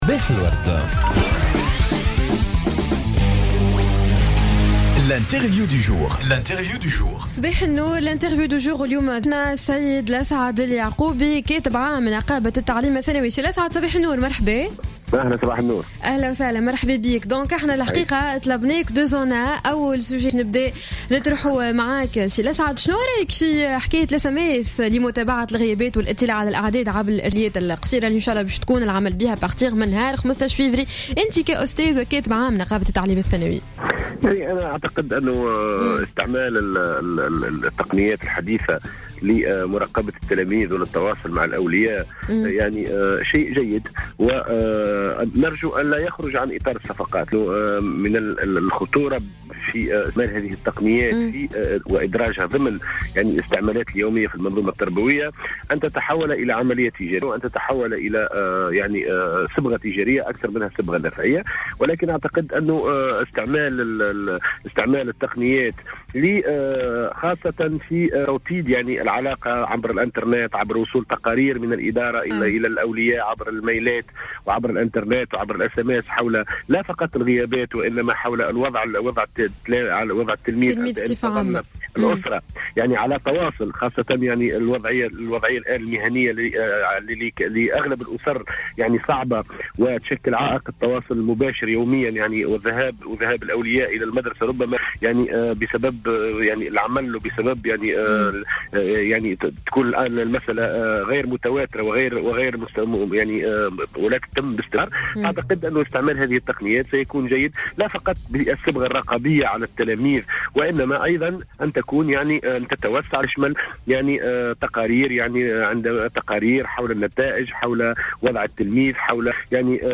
في مداخلة له على الجوهرة "اف ام" صباح اليوم